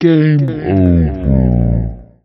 ゲームオーバー効果音。